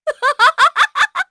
Seria-Vox_Happy2_kr.wav